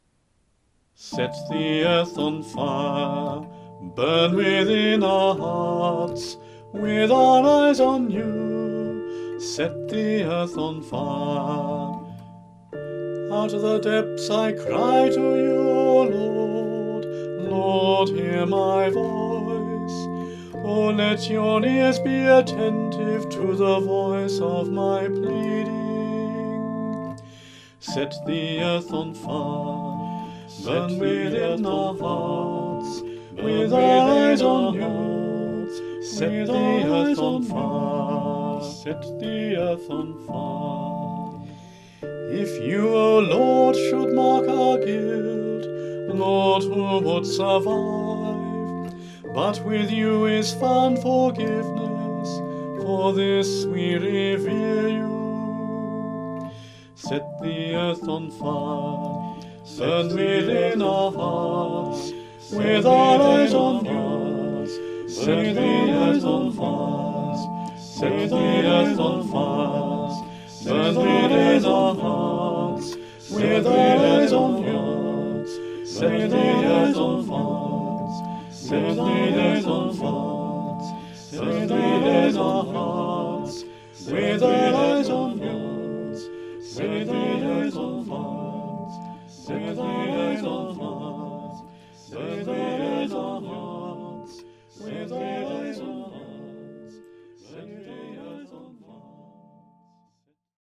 The antiphon may be sung as a round, as indicated.
A demo recording extract will be found here.